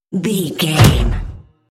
Trailer dramatic hit
Sound Effects
Atonal
heavy
intense
dark
aggressive